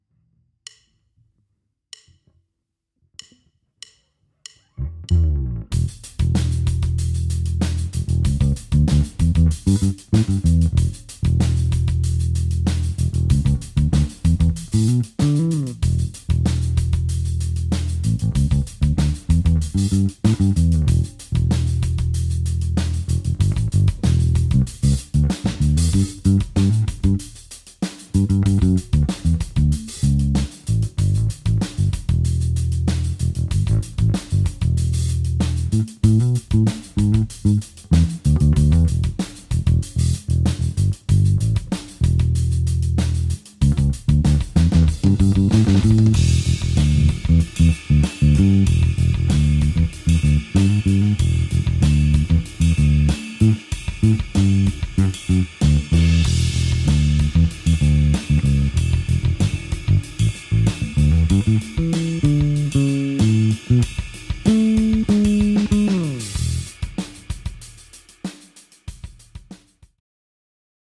Nahrávky v mixu, struny Thomastik Infeld Jazz ... hlazenky
Mix 2
Linka - Focusrite - VST lampový preamp cca. styl Ampeg B15R